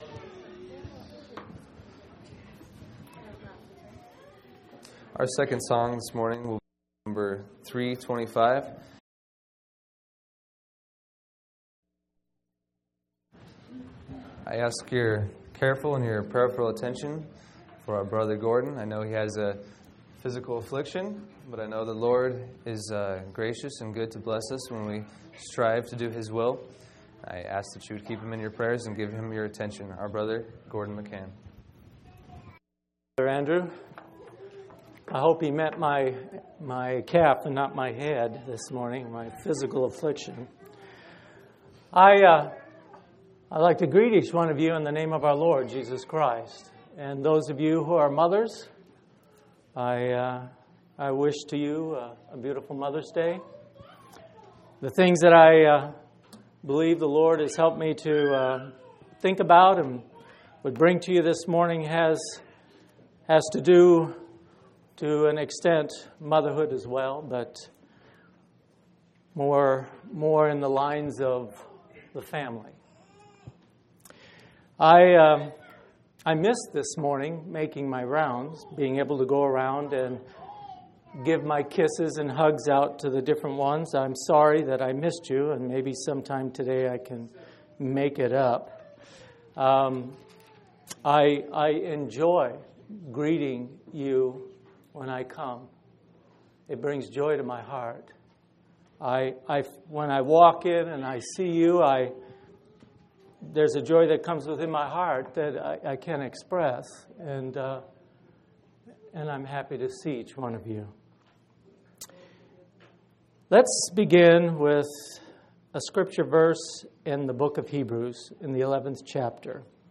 5/8/2005 Location: Phoenix Local Event